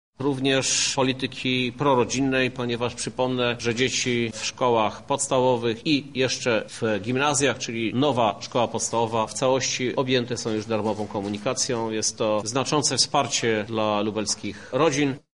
Działanie wpisuje się w kształtowanie polityki senioralnej – mówi prezydent Lublina, Krzysztof Żuk: